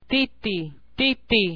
Table I: Sonorised Official Alphabet
Alveolar   Alveo-
Simple occlusives p